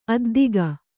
♪ addiga